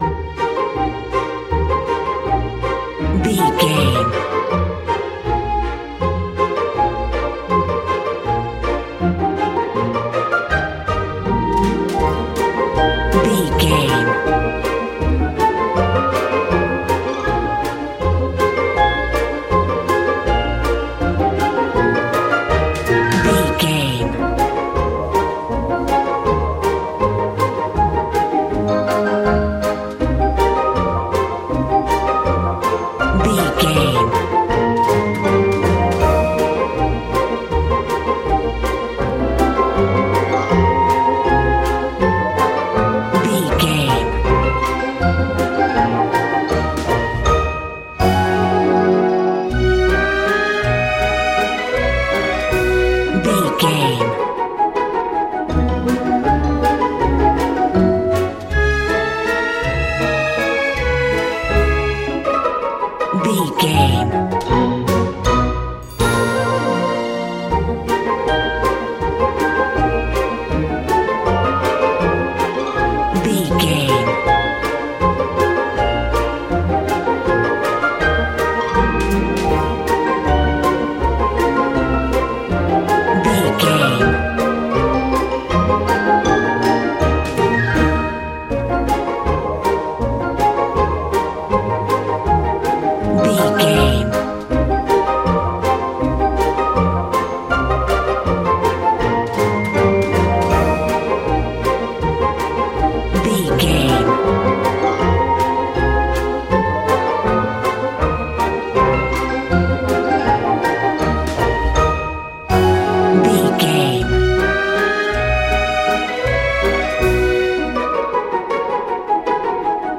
Old-fashioned atmosphere.
Ionian/Major
A♭
cheerful/happy
orchestra
strings
playful